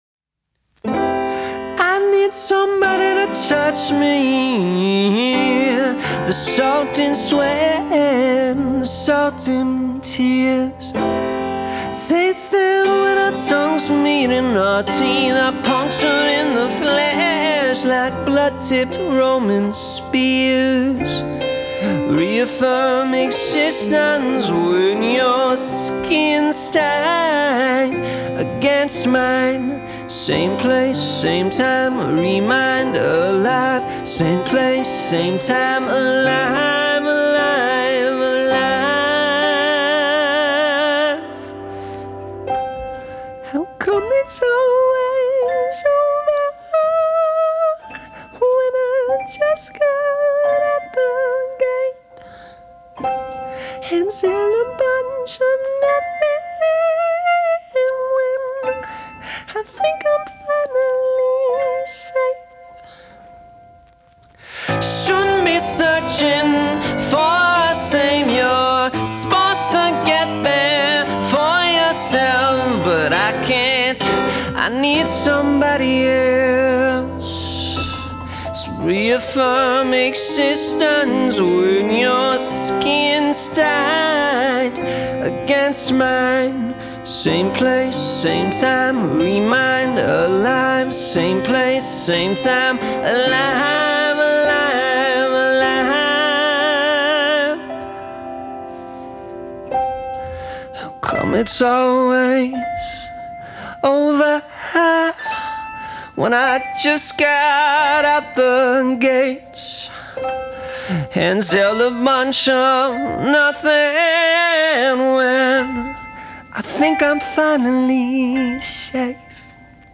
recorded live in the summer of 2023